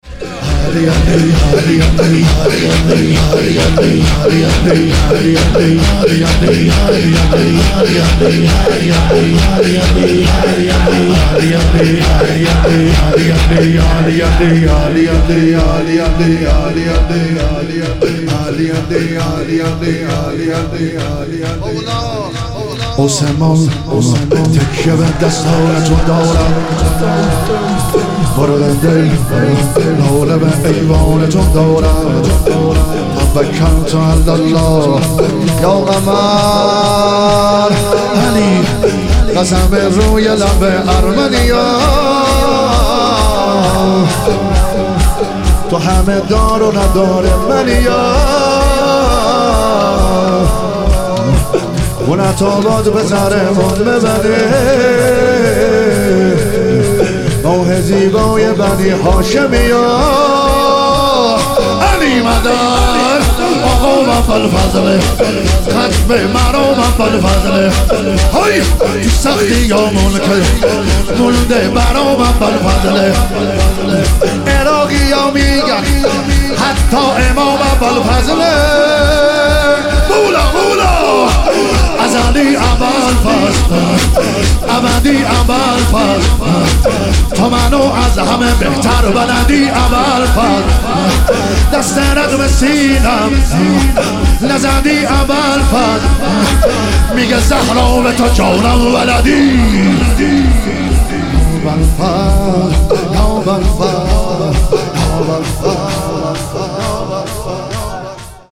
تخریب بقیع - شور